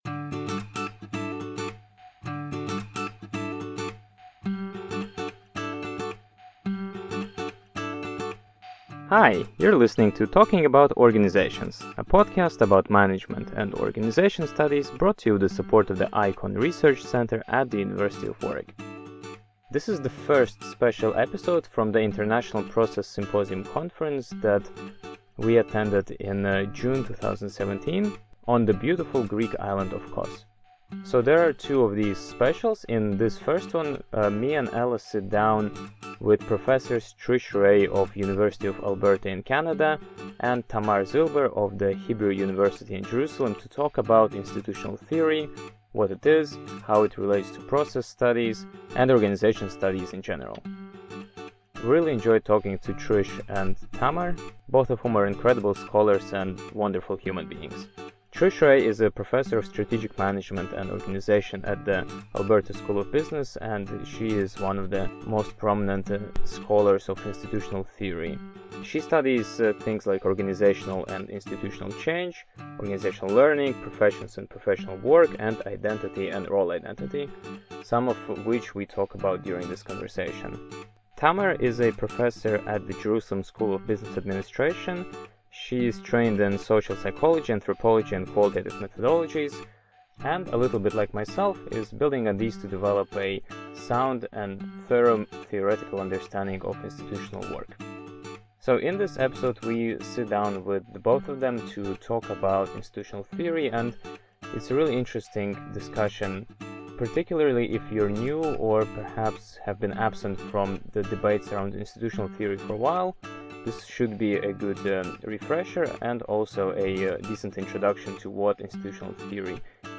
Please join us for the first of two fascinating special episodes recorded from the International Process Symposium 2017. The aim of the Symposium is to consolidate, integrate, and further develop ongoing efforts to advance a sophisticated process perspective in organization and management studies.